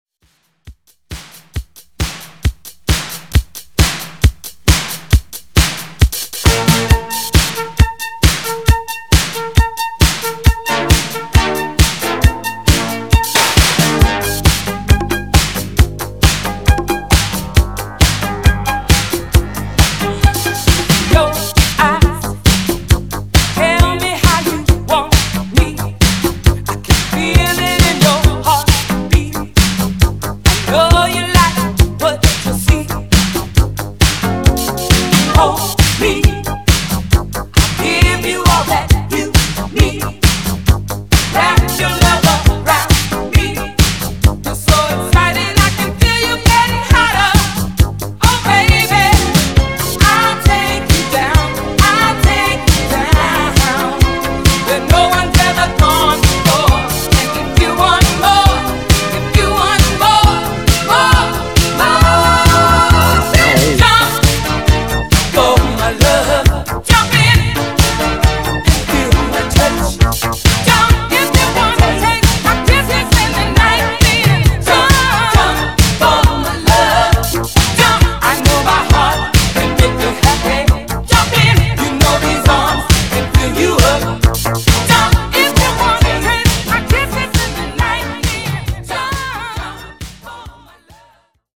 Genre: 80's
Clean BPM: 135 Time